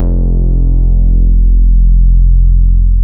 MODULAR F2M.wav